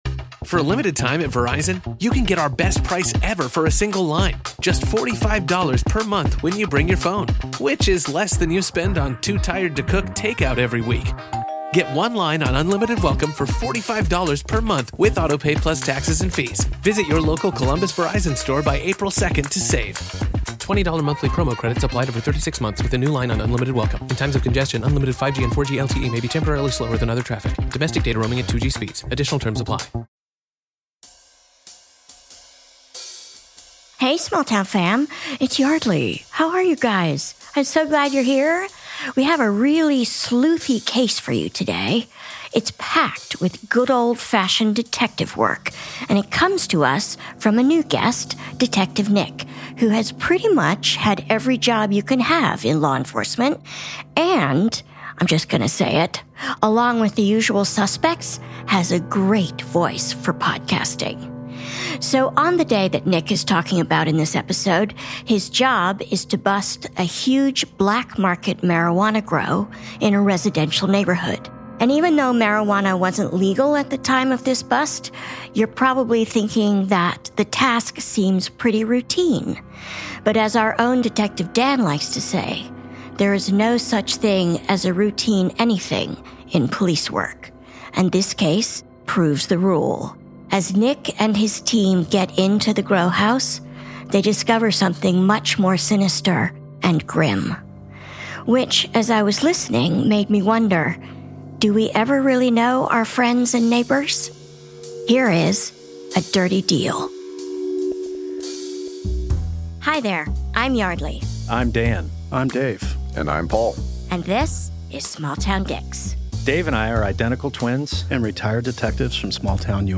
Guest detective